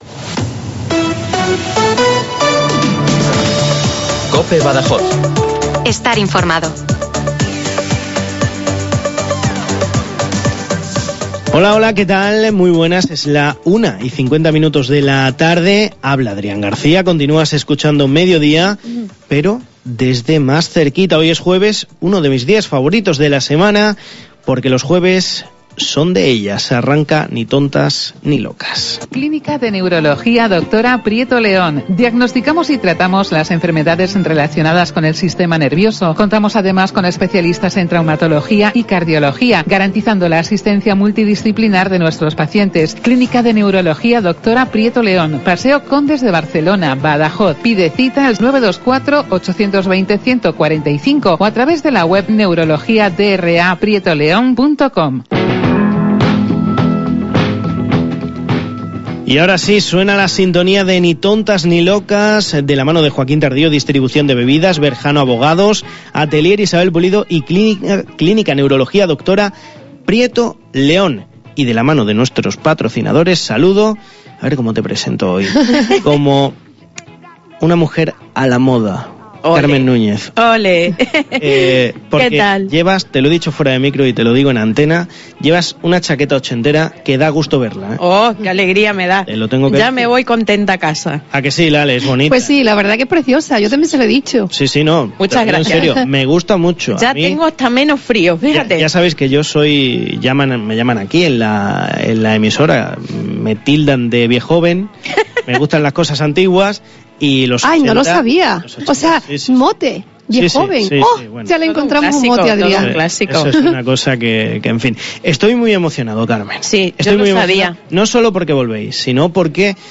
Hoy hemos tenido una entretenida charla con nuestra Olímpica en Triatlón y Duatlòn de Badajoz Miriam Casillas García.